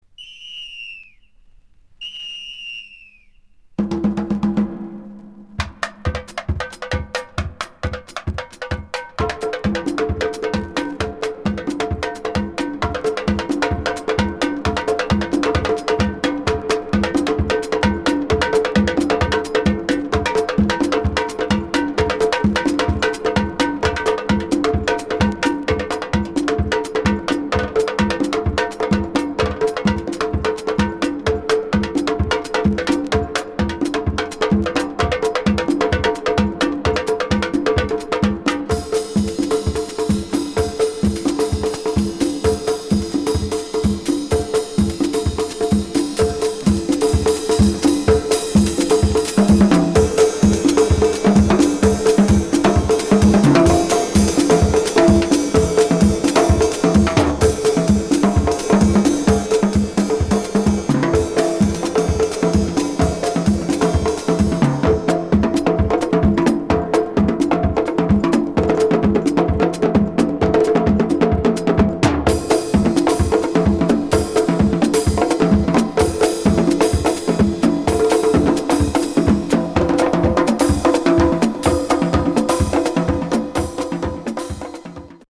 dancefloor filler
Also featuring ten good latin tunes.